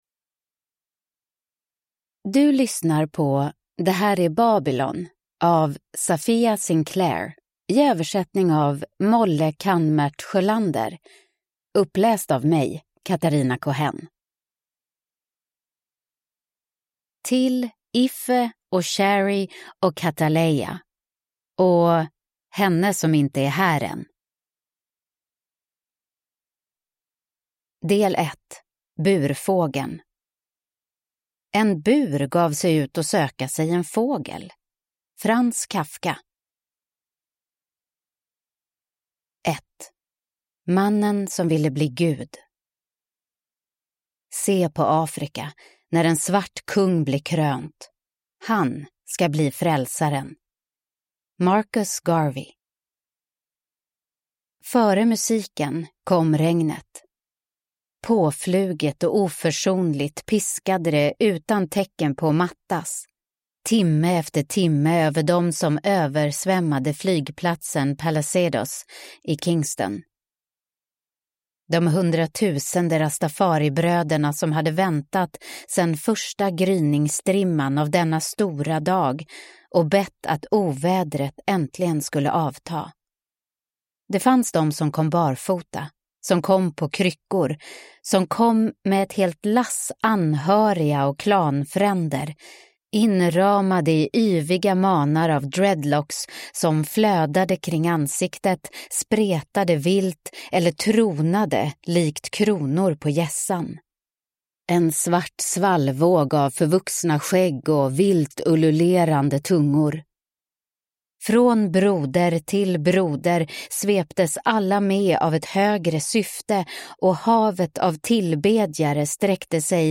Det här är Babylon : en biografi – Ljudbok – Laddas ner